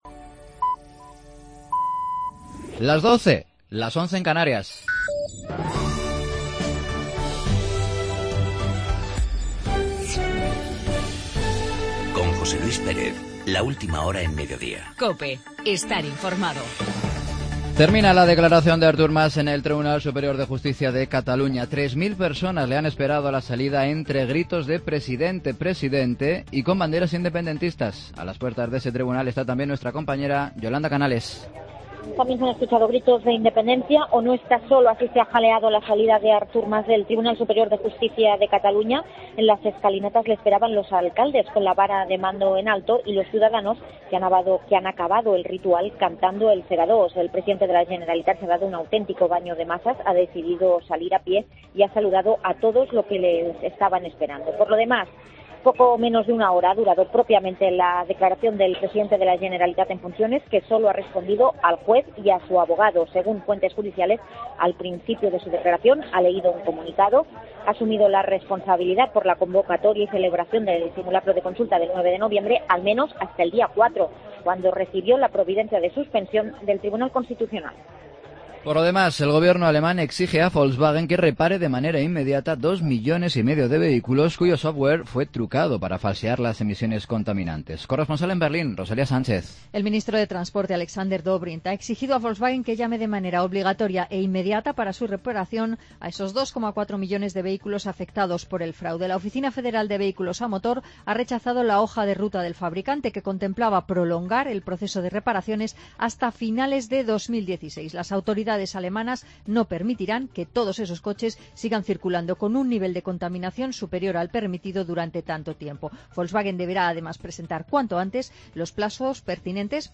Noticias de las 12.00 horas, jueves 15 de octubre de 2015